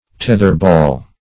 Tether-ball \Teth"er-ball`\, n.